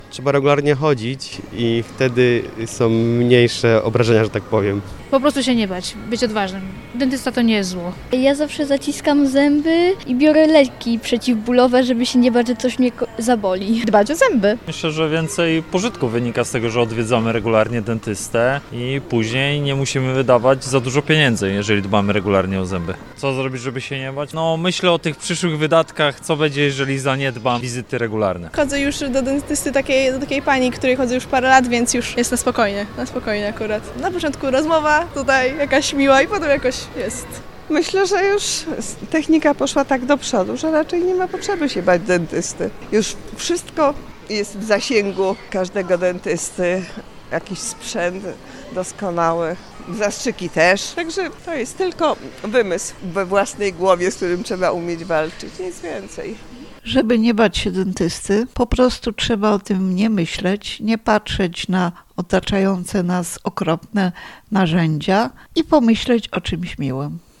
Zapytaliśmy o to Dolnoślązaków.
W jaki sposób pokonać obawy? Doradzają Dolnoślązacy.